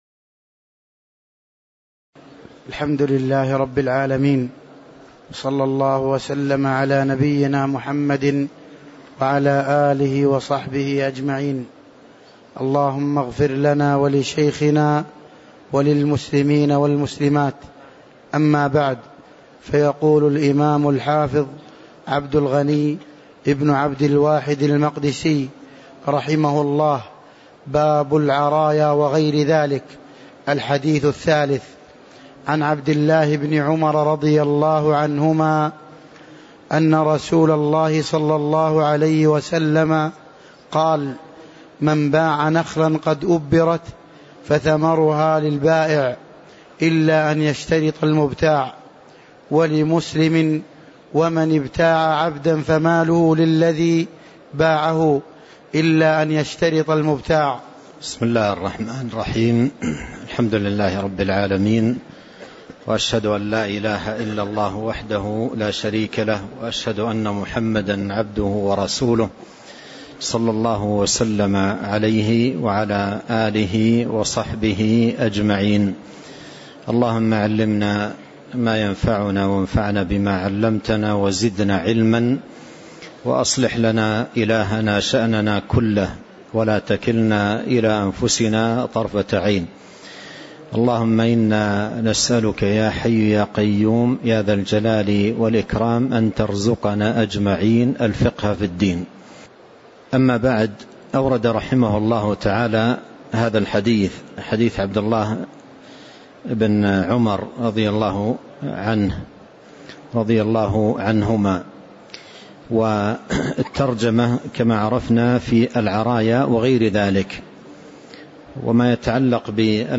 تاريخ النشر ٣ رجب ١٤٤٤ هـ المكان: المسجد النبوي الشيخ